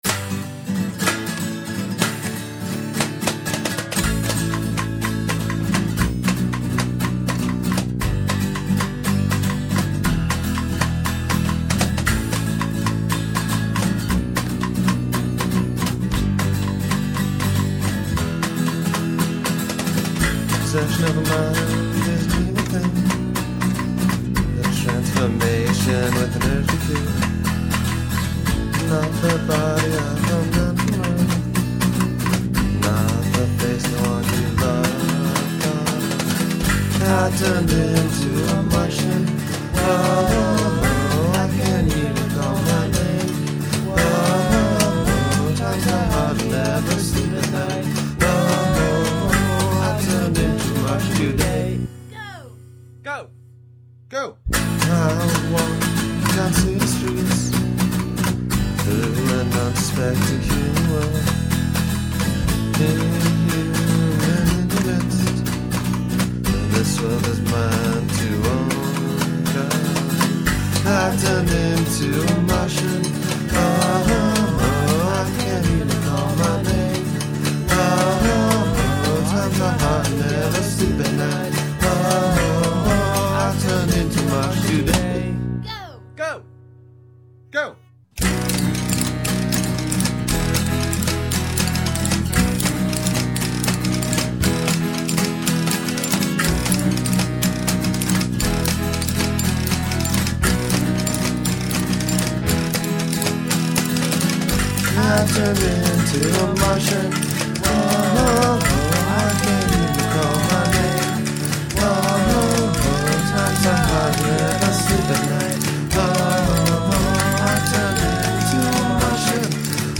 hidden bonus track (our cover